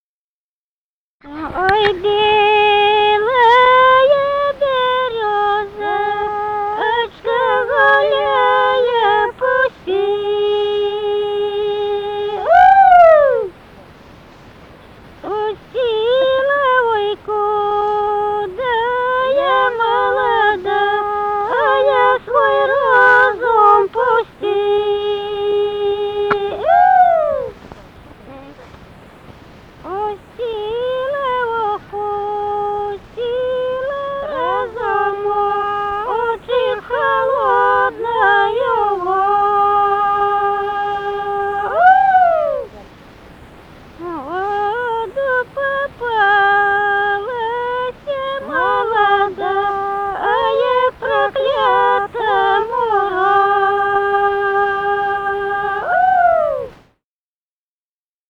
Музыкальный фольклор Климовского района 009. «Ой, белая берёзочка» (весенняя).
Записали участники экспедиции